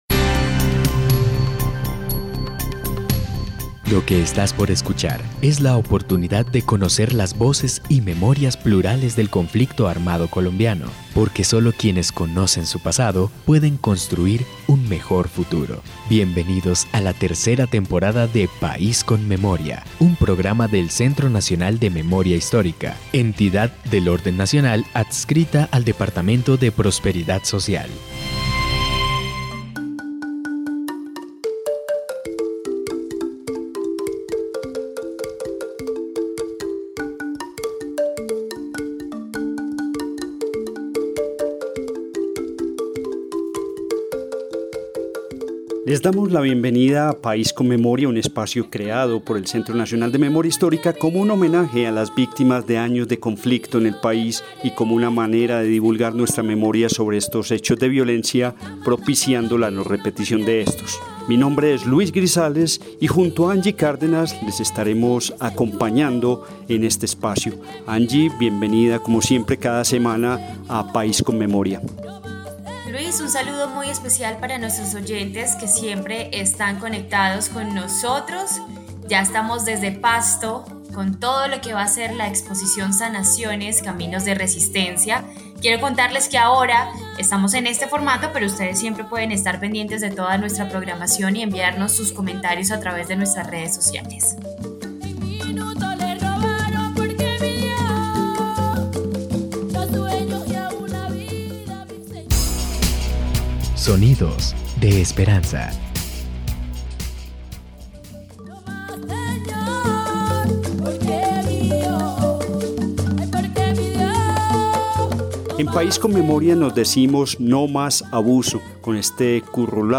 Descripción (dcterms:description) Capítulo número 32 de la tercera temporada de la serie radial "País con Memoria".